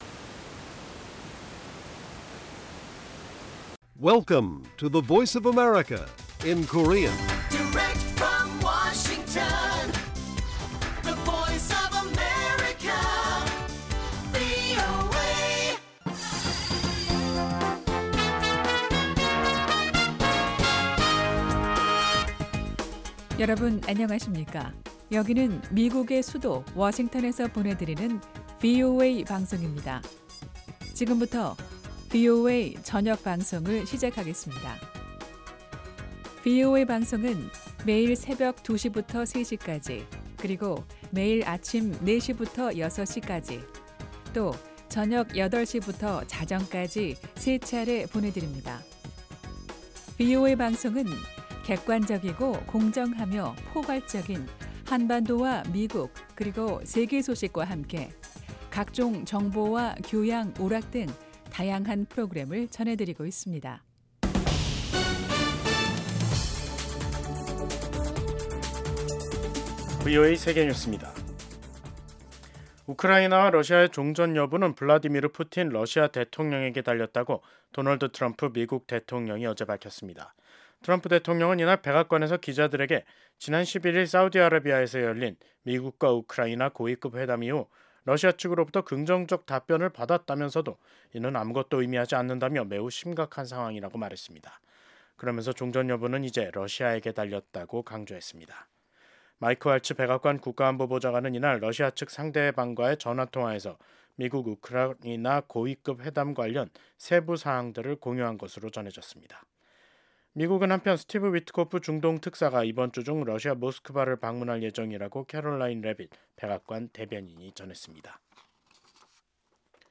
VOA 한국어 간판 뉴스 프로그램 '뉴스 투데이', 2025년 3월 13일 1부 방송입니다. 도널드 트럼프 대통령이 한반도를 포함한 역내 문제를 담당하는 국무부 동아태 치관보에 마이클 디섬브레 전 태국 대사를 지명했습니다. 한국 정치권에서 핵잠재력 확보 주장이 제기된 가운데 국무부가 동맹에 대한 미국의 방위 공약을 거듭 강조했습니다. 미국 해병대는 한반도 위기 발생 시 방어를 지원할 준비가 돼 있다고 크리스토퍼 마호니 해병대 부사령관이 밝혔습니다.